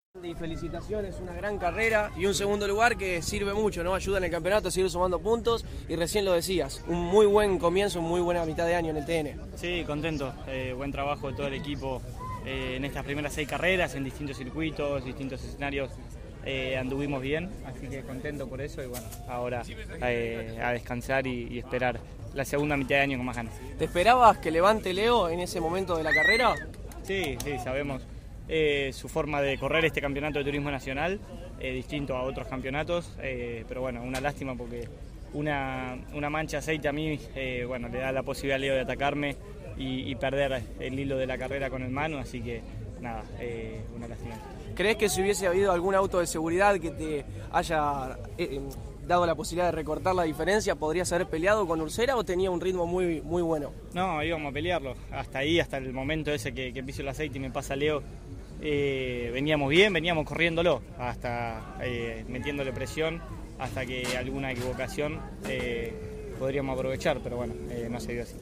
Las actividades de la sexta fecha de la temporada del Turismo Nacional en Posdas, Misiones, se cerró con la final de la Clase 3 que, tras ella, CÓRDOBA COMPETICIÓN dialogó con los protagonistas.